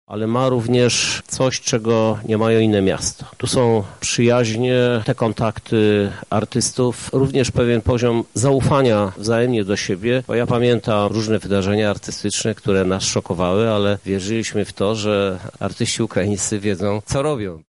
Ta idea współpracy kulturalnej z naszymi sąsiadami ma swoją historię -stwierdza Krzysztof Żuk: